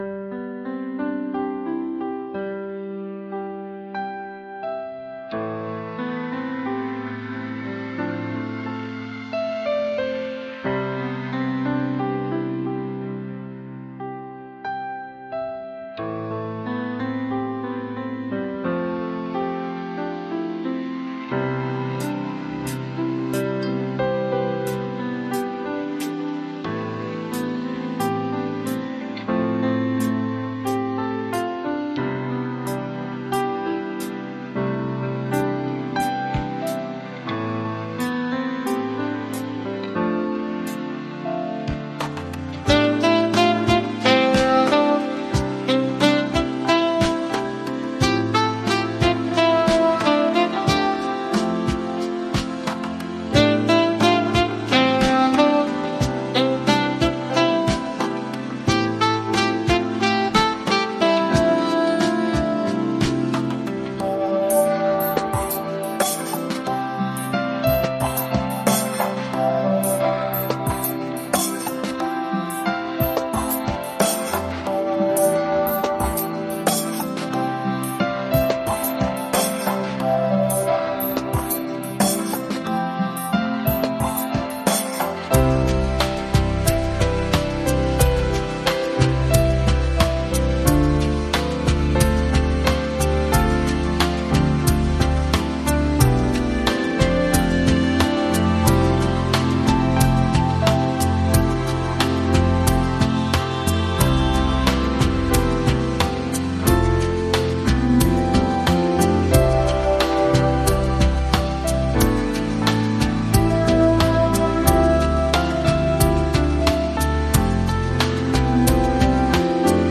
【構成】 ・イントロは、穏やかなピアノのリフから始まり、軽やかなブラシドラムが徐々に加わります。